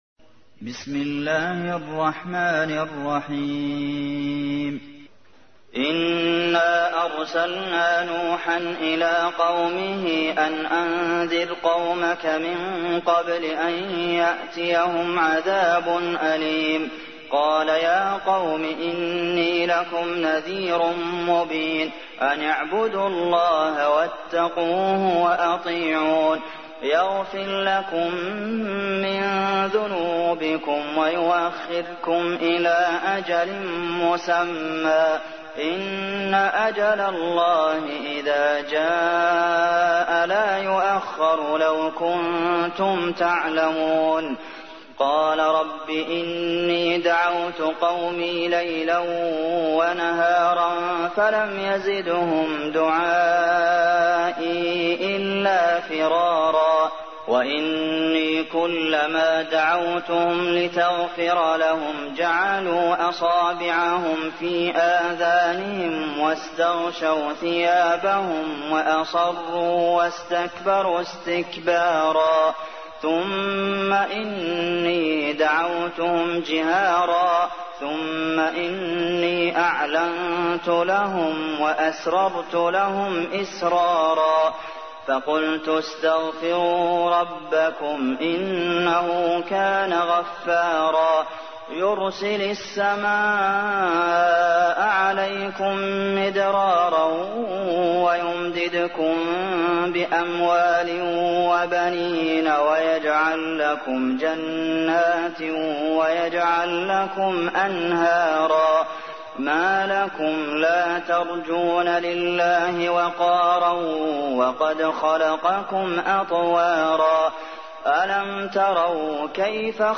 تحميل : 71. سورة نوح / القارئ عبد المحسن قاسم / القرآن الكريم / موقع يا حسين